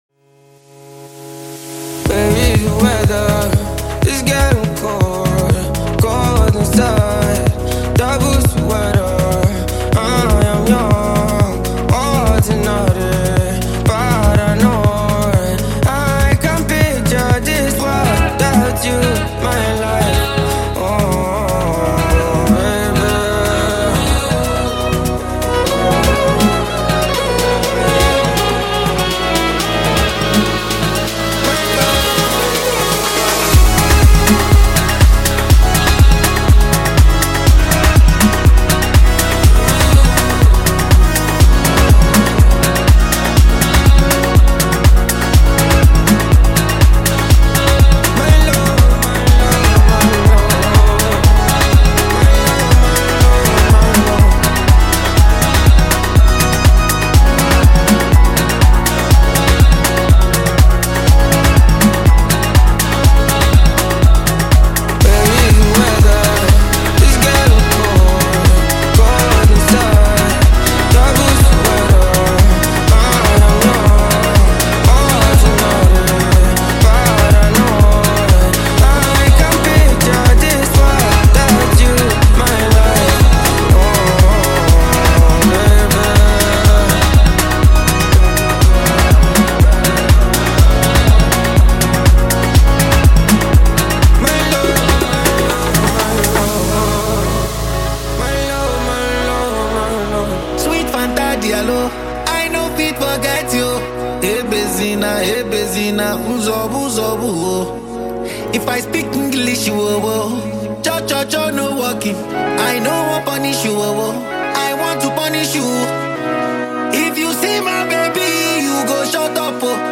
a singer and songwriter of Afrobeat music